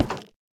Minecraft Version Minecraft Version 1.21.5 Latest Release | Latest Snapshot 1.21.5 / assets / minecraft / sounds / block / nether_wood_fence / toggle3.ogg Compare With Compare With Latest Release | Latest Snapshot